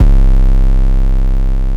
TM88 HypeMode808.wav